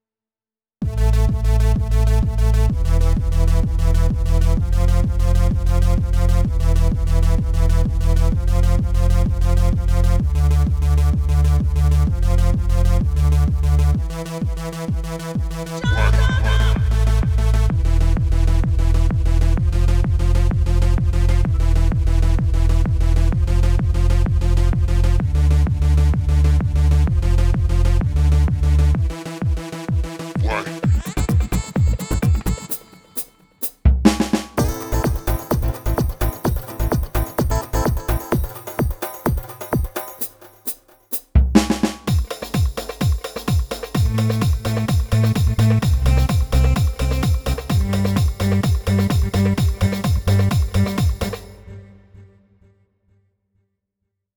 מה אני צריך לשפר נשמע ריק מאוד להוסיף בסים ולמלא
בפרט שאתה בונה עם דגימות של האורגן (ימהאה נכון…